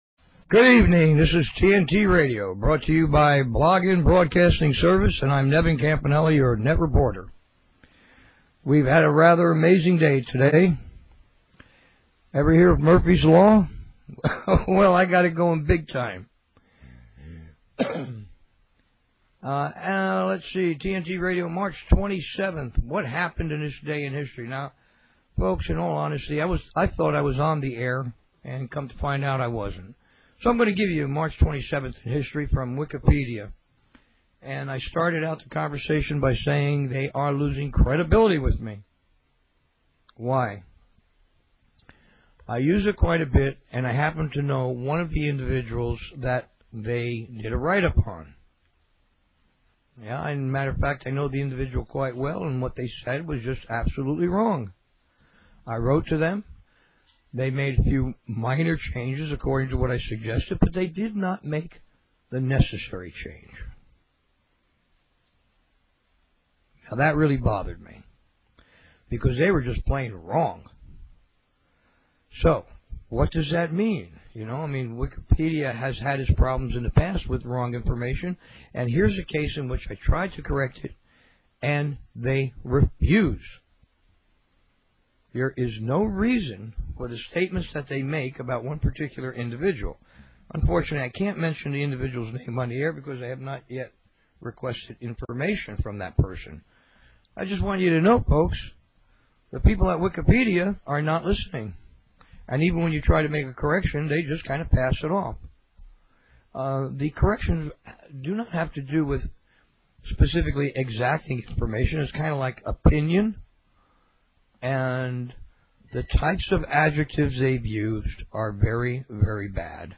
Talk Show Episode, Audio Podcast, TNT_Radio and Courtesy of BBS Radio on , show guests , about , categorized as